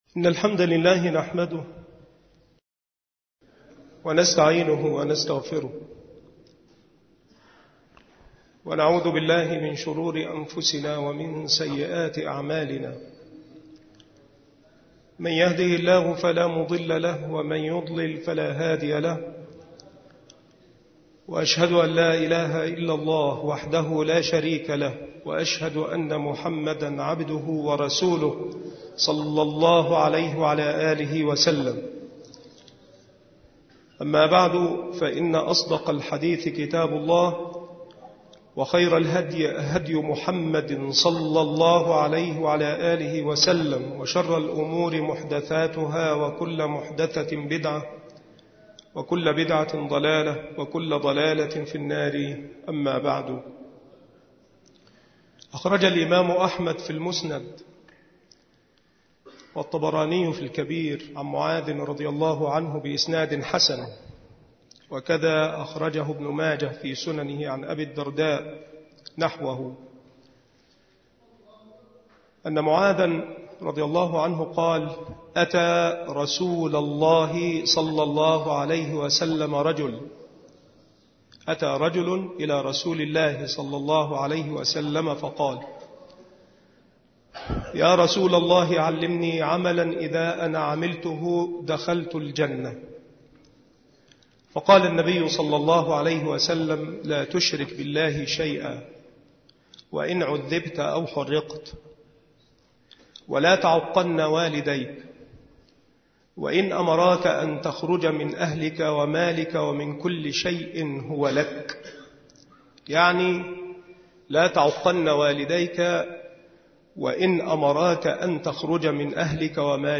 مكان إلقاء هذه المحاضرة بمسجد صلاح الدين بمدينة أشمون - محافظة المنوفية